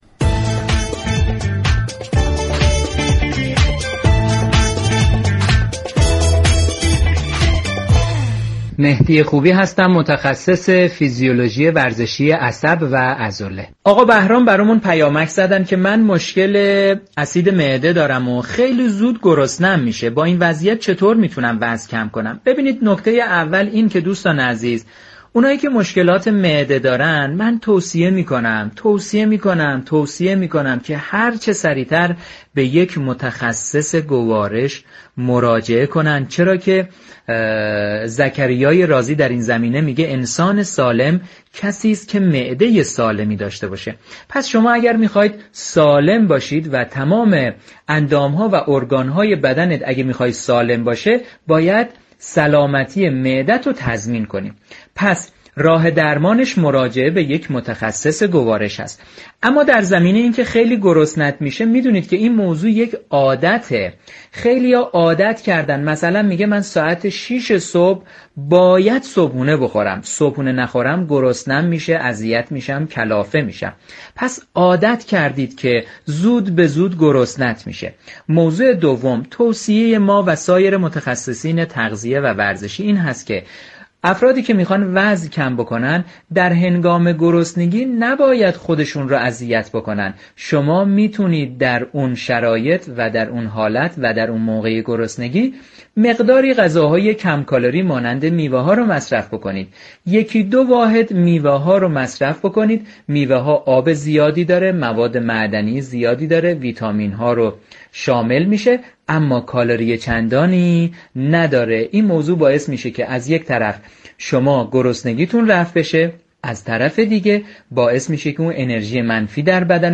متخصص فیزیولوژی ورزشی